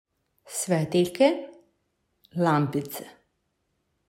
2. Svetiljke/lampice (click to hear the pronunciation)